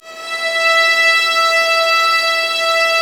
Index of /90_sSampleCDs/Roland - String Master Series/STR_Vlas Bow FX/STR_Vas Sul Pont